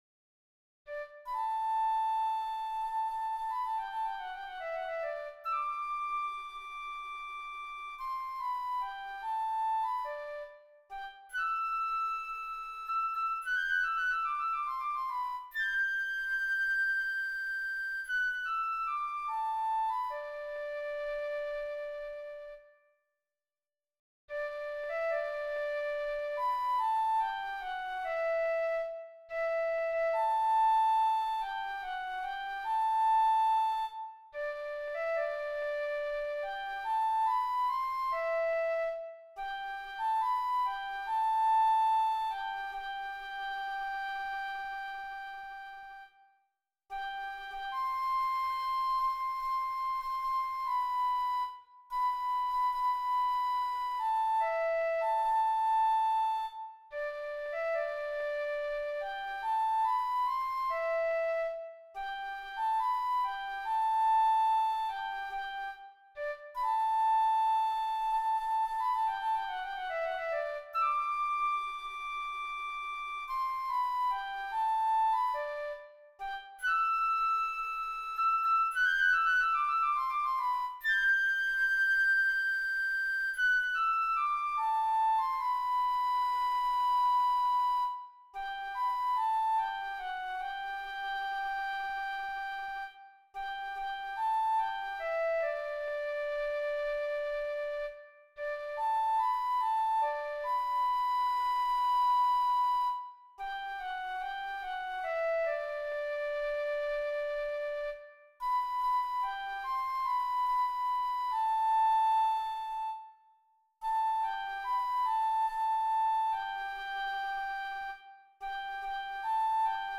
Flute and Piano
Hymn arrangement